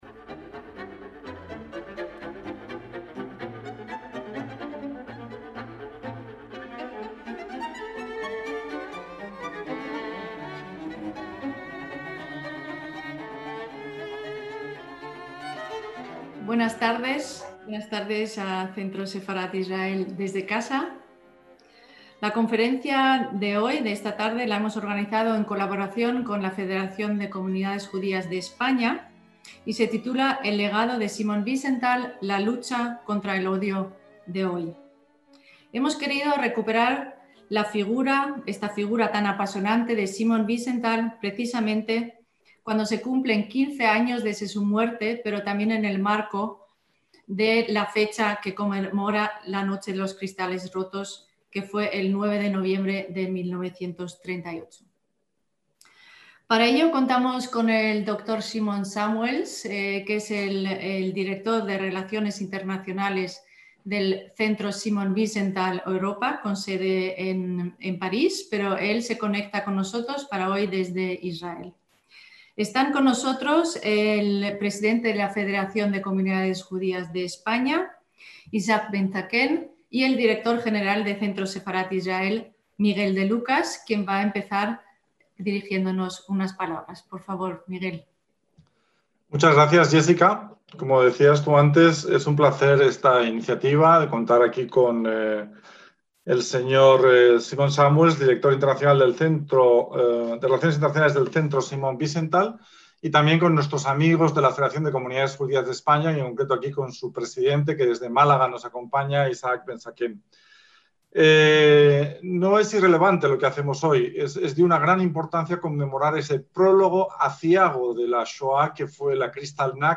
ACTOS "EN DIRECTO" - Cuando se cumplen 15 años de la muerte del investigador Simon Wiesenthal, el Centro Simon Wiesenthal, la Federación de Comunidades Judías de España y el Centro Sefarad-Israel proponen una reflexión en torno a su legado y la importancia de su pensamiento en la lucha contra el odio hoy. Tras estar prisionero en el campo de Mauthausen-Gusen, Wiesenthal dedicó gran parte de su vida a identificar y perseguir a criminales nazis. La conferencia